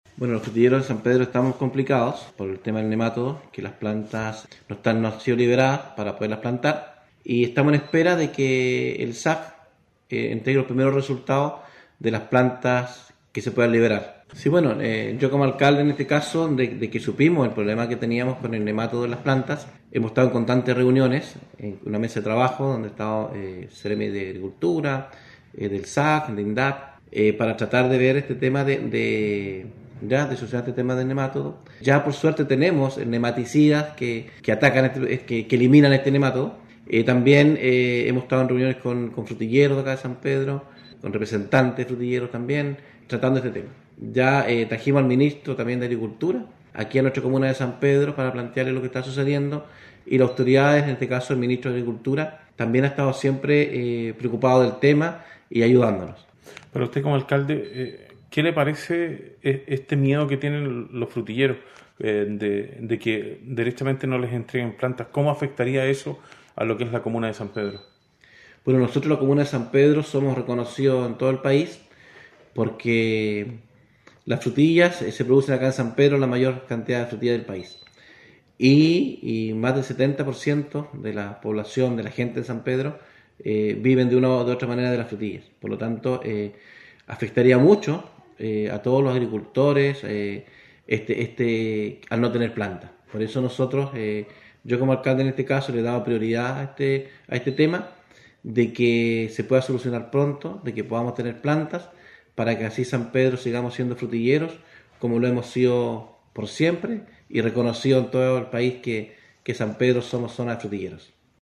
DECLARACION-01-ALCALDE.mp3